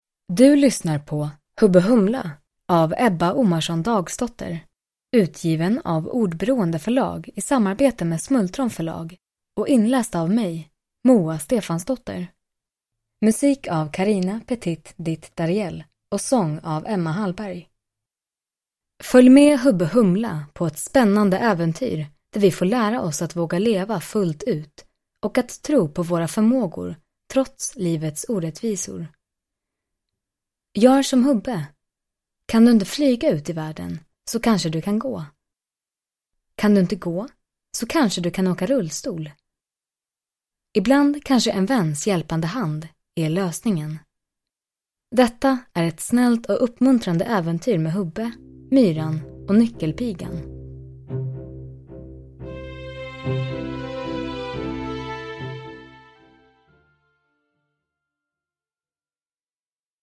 Hubbe Humla – Ljudbok – Laddas ner